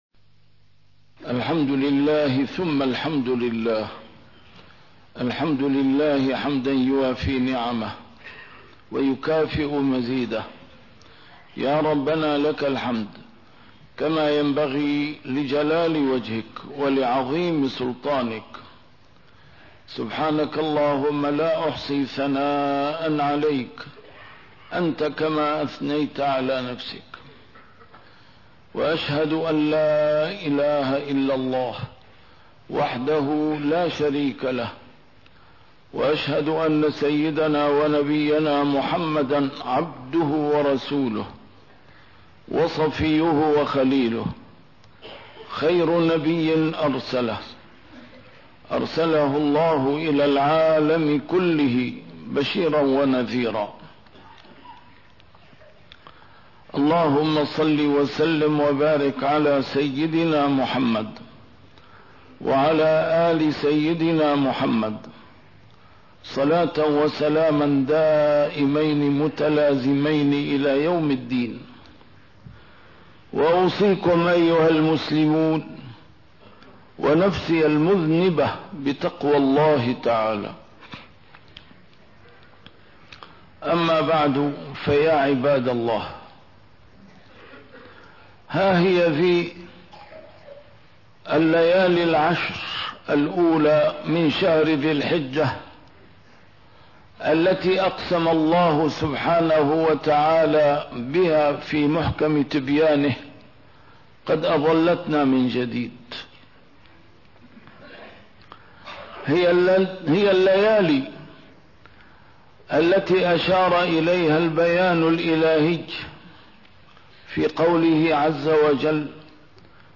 A MARTYR SCHOLAR: IMAM MUHAMMAD SAEED RAMADAN AL-BOUTI - الخطب - ((وَالْفَجْرِ وَلَيَالٍ عَشْرٍ))